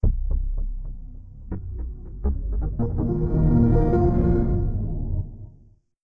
MinderiaOS Pre-Delt Startup.wav